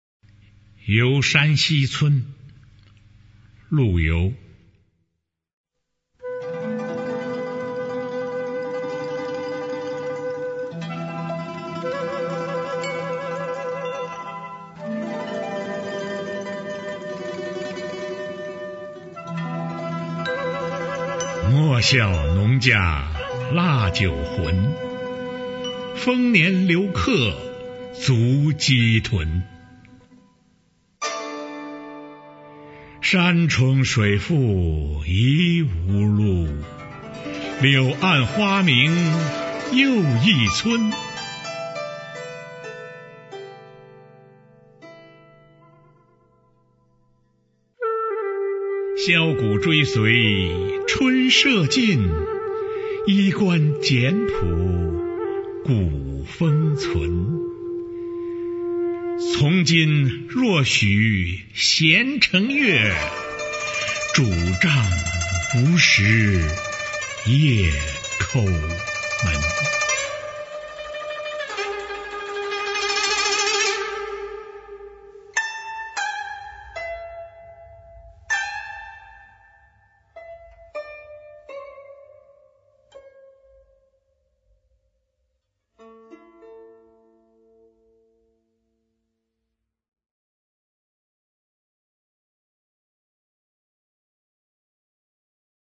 《游山西村》朗读